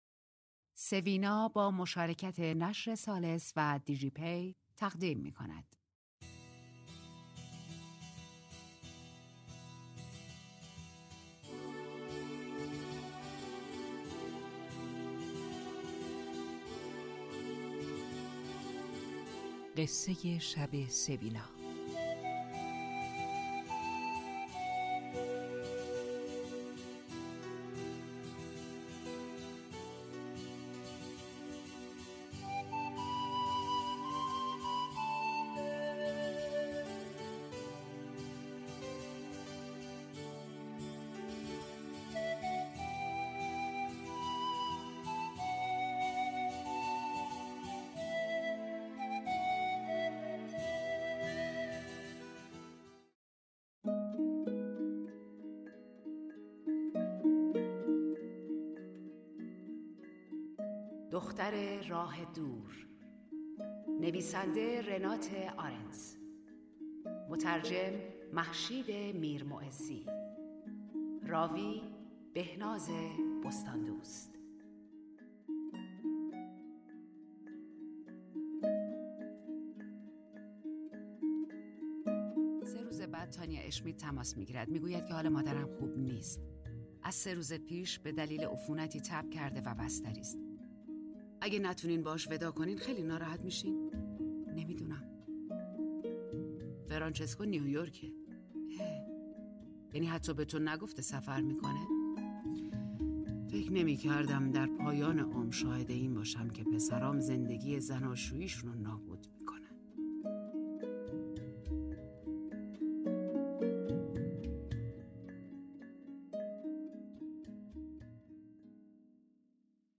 خوانش رمان دختر راه دور